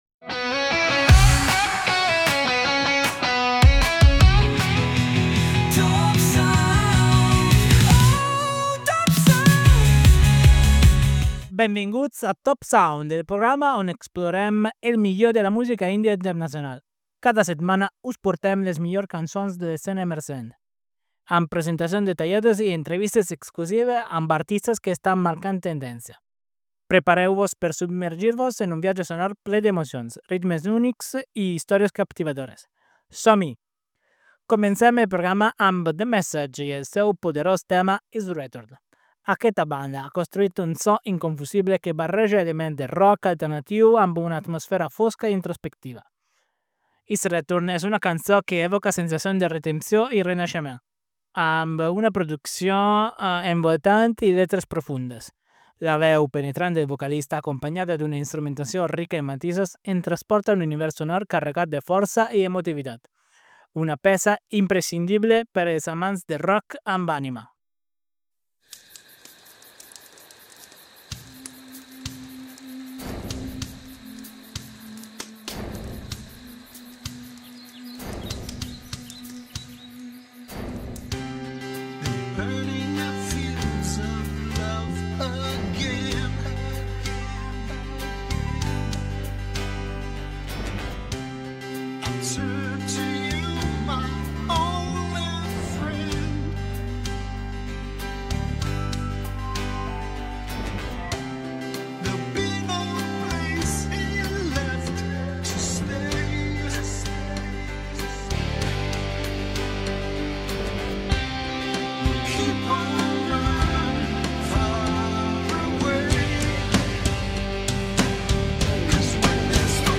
Never Gonna Quit INTERVIEW